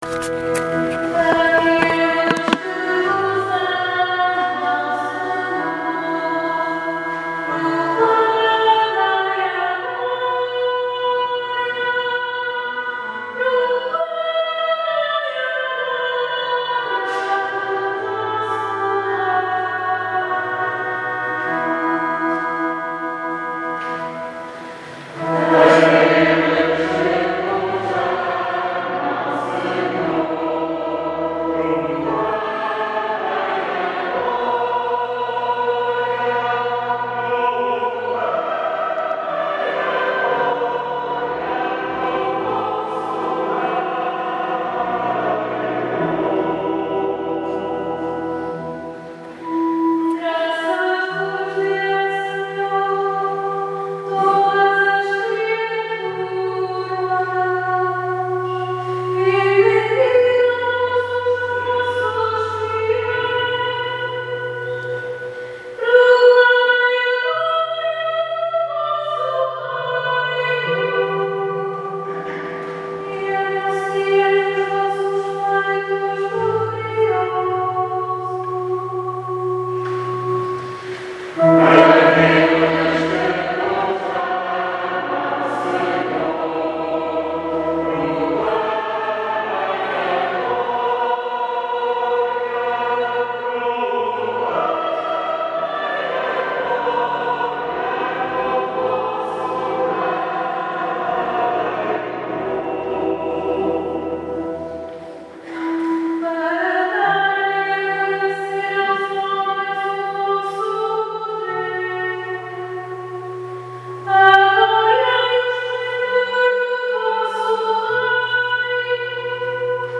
Grupo Coral de Veiros na Eucaristia de 24 de Agosto de 2020
├uudio-2-Salmo.mp3